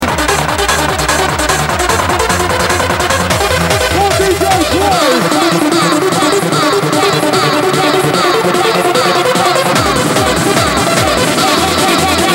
old hardtrance tune id....4